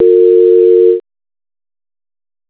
linebusy.raw